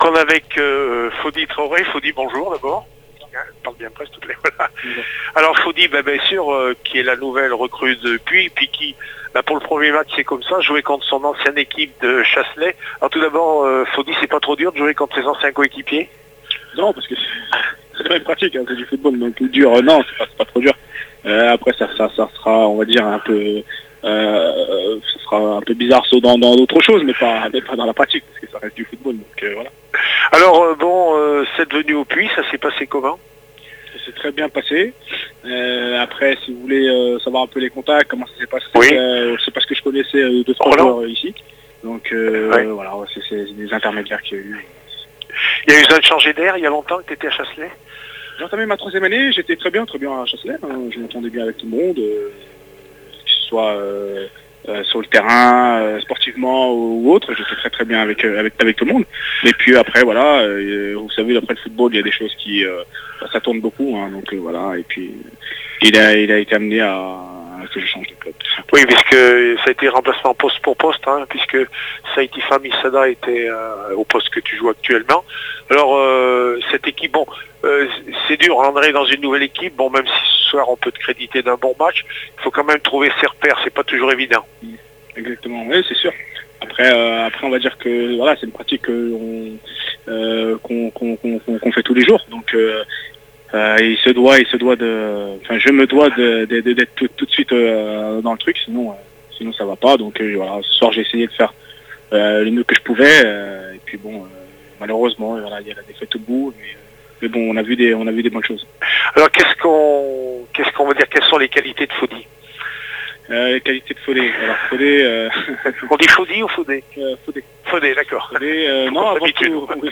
REACTION